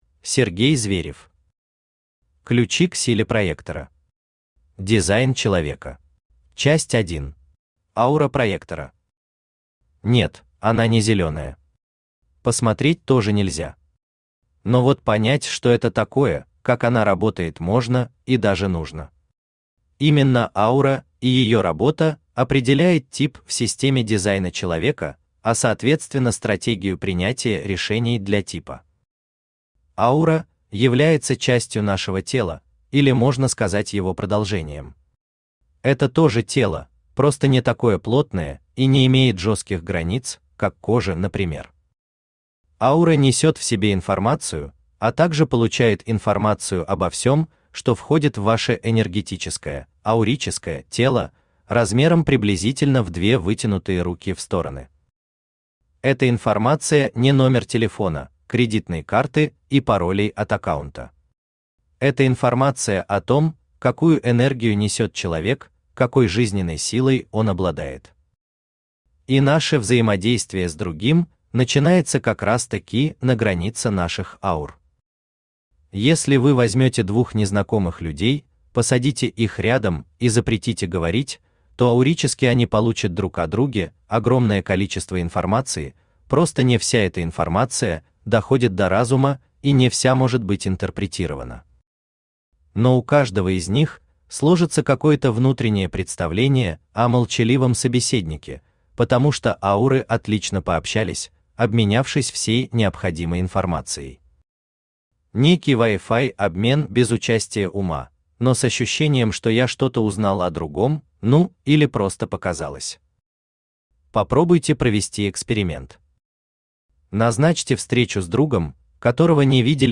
Аудиокнига Дизайн Человека. Ключи к силе Проектора | Библиотека аудиокниг
Ключи к силе Проектора Автор Сергей Зверев Читает аудиокнигу Авточтец ЛитРес.